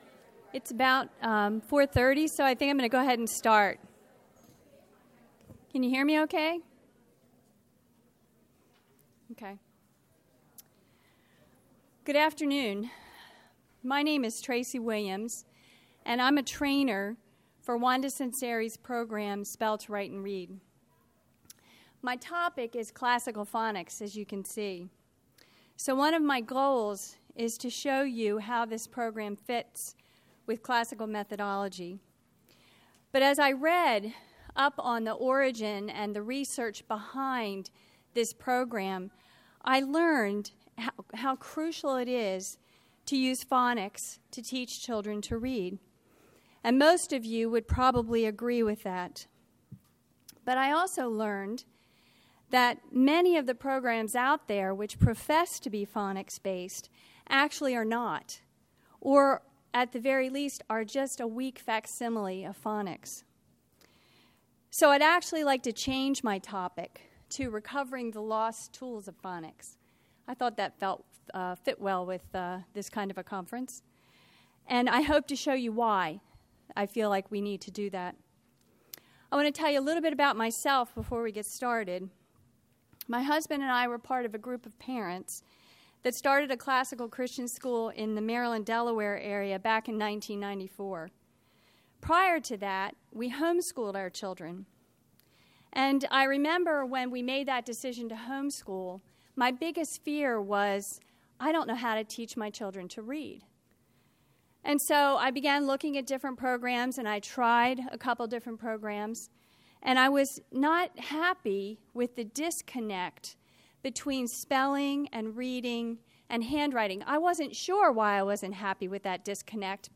2012 Workshop Talk | 1:02:15 | K-6, Literature
Jan 21, 2019 | Conference Talks, K-6, Library, Literature, Media_Audio, Workshop Talk | 0 comments